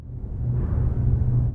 factoryPowerup.wav